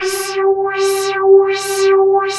27 VOICES -R.wav